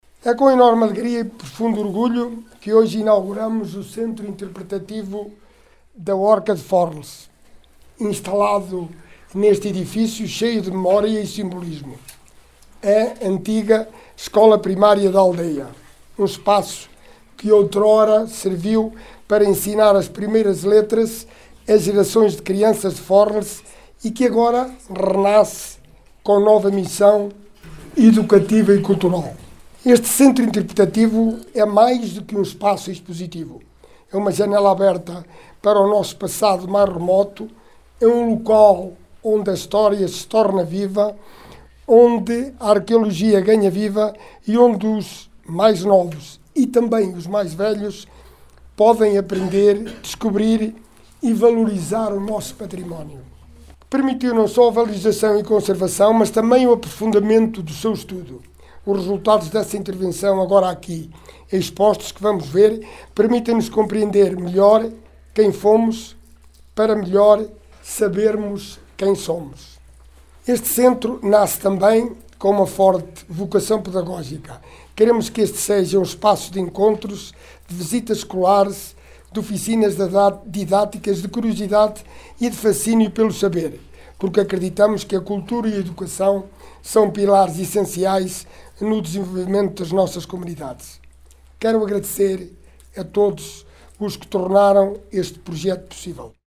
Alexandre Vaz, Presidente do Município de Sátão, no momento da inauguração, referiu o renascimento deste espaço, “com uma nova missão educativa e cultural…”.